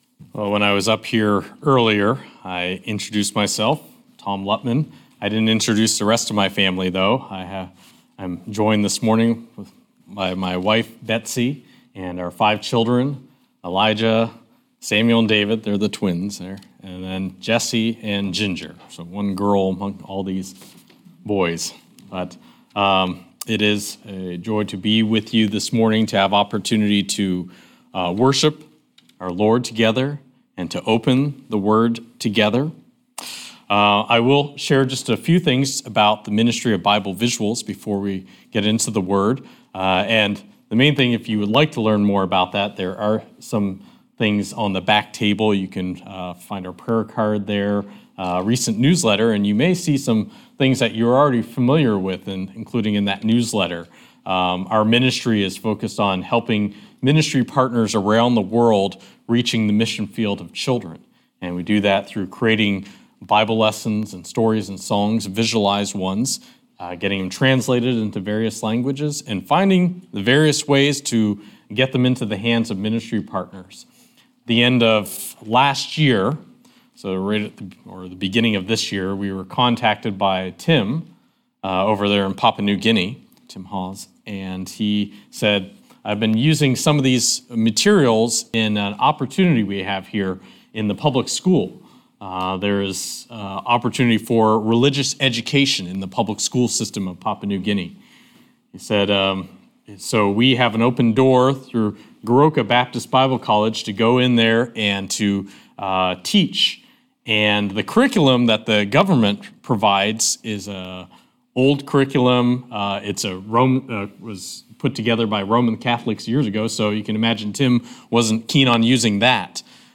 Luke 6:27-36 Service Type: Morning Service « Wednesday Night Challenge